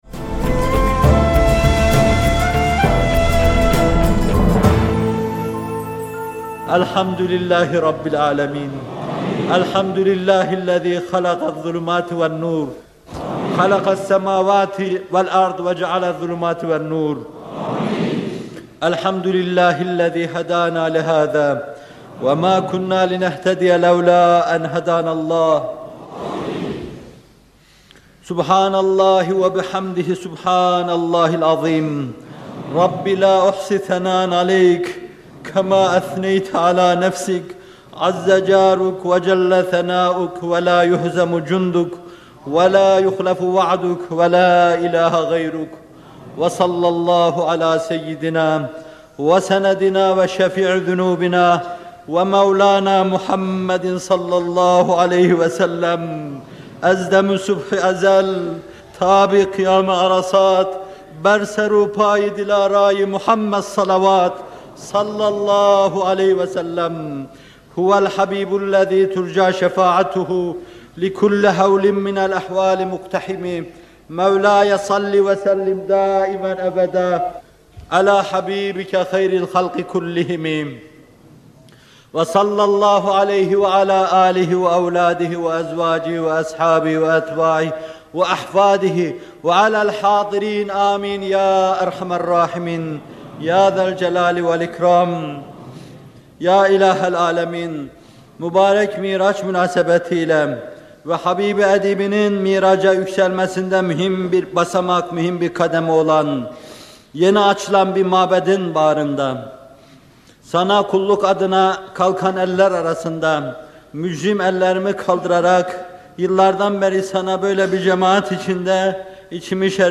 Muhterem Fethullah Gülen Hocaefendi’nin 1986 yılında Çamlıca Camii’nde “Kalbin Miracı” konulu vaazında yaptığı dua.